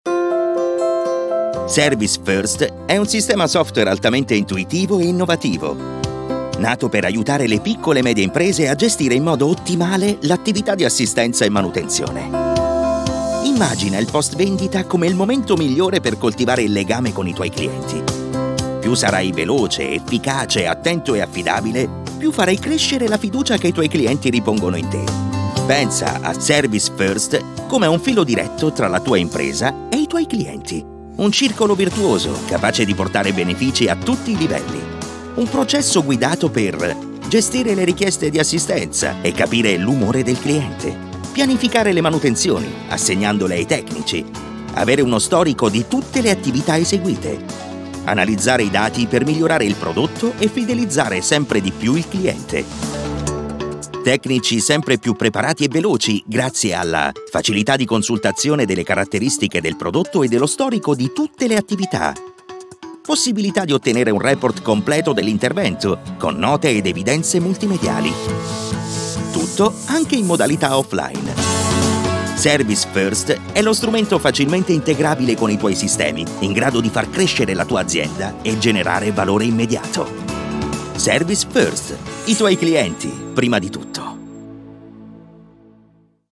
and i'm a Professional Italian Voiceover with baritonal and adaptable voice for each project
Sprechprobe: Industrie (Muttersprache):
I have a Home Studio and all the knowledge to edit and send professional, quality audio.